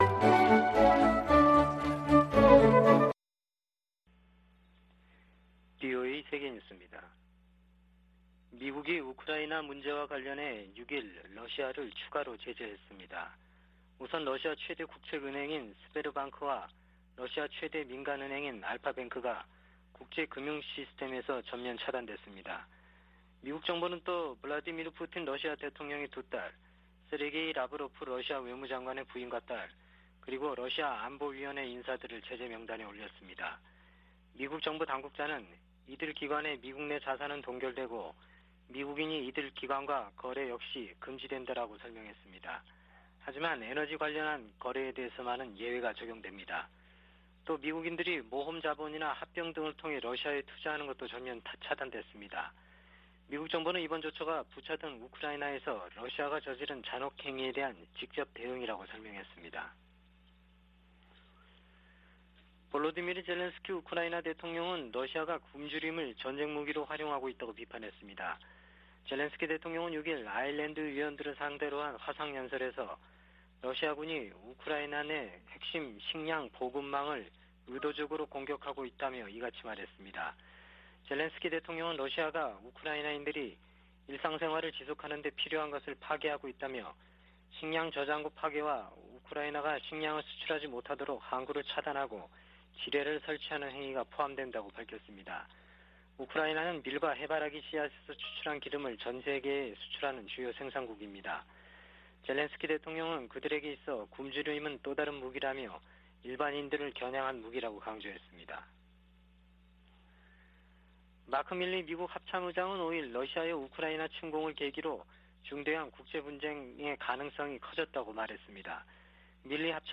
VOA 한국어 아침 뉴스 프로그램 '워싱턴 뉴스 광장' 2022년 4월 7일 방송입니다. 미 국무부는 탄도미사일 발사가 북한을 더욱 고립시키고 한반도 안정을 해치는 행위일 뿐이라고 지적했습니다. 북한의 탄도미사일 역량이 미 본토에까지 실질적인 위협이 되고 있다고 미 합참의장이 평가했습니다. 백악관 국가안보보좌관은 미국을 방문한 한미정책협의대표단을 면담하고 정상회담 조기 개최와 전략자산 배치 등에 대해 논의한 것으로 알려졌습니다.